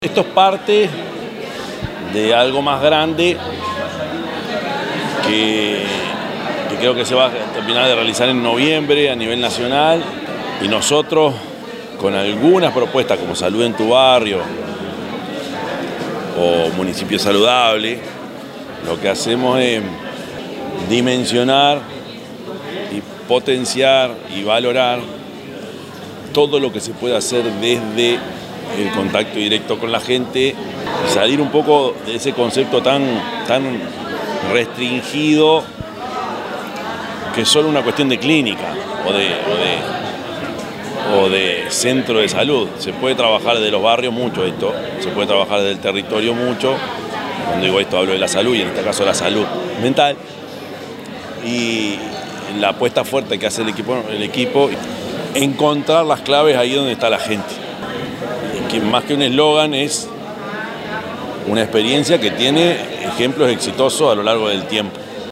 Se realizó la primera conferencia preparatoria Salud mental y abordajes comunitarios, organizada por la Universidad de la República y Apex y coorganizada por la Intendencia de Canelones a través de la Dirección de Salud, entre otras instituciones.